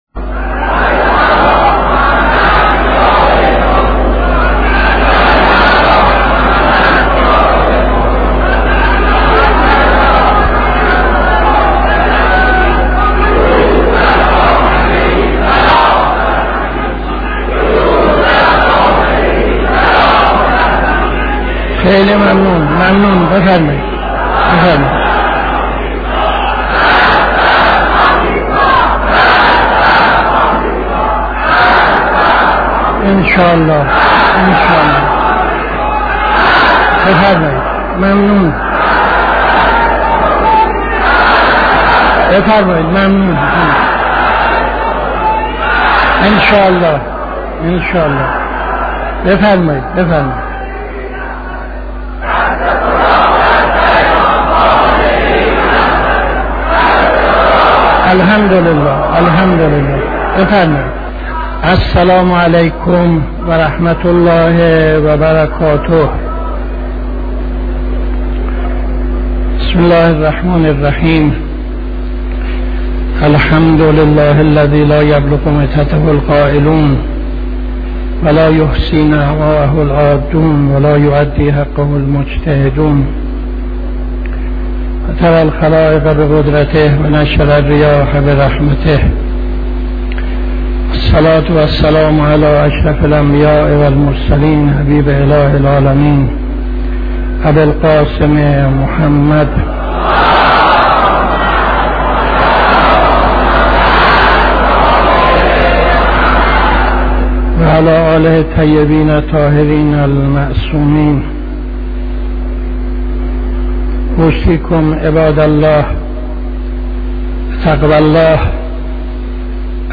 خطبه اول نماز جمعه 26-02-76